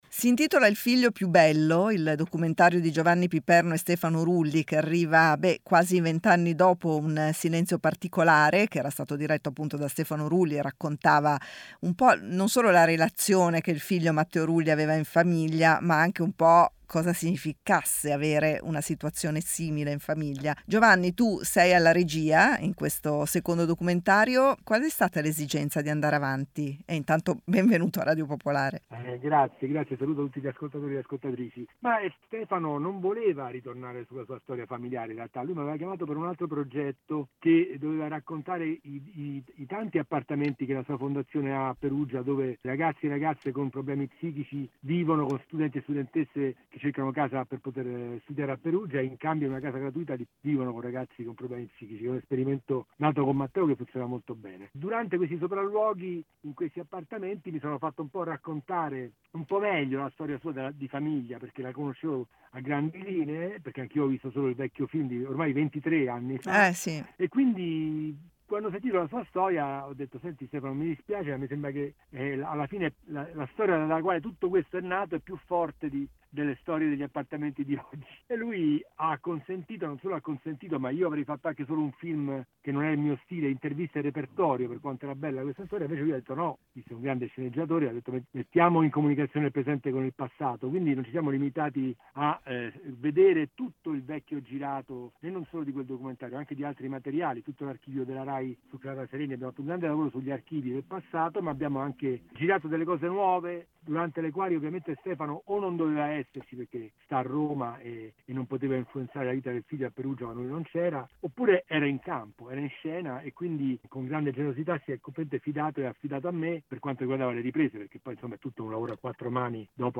Oltre a una riflessione sulla Salute Mentale e su come viene affrontata in Italia, “Il figlio più bello” è anche un’analisi universale sulla relazione tra un padre e un figlio. L'intervista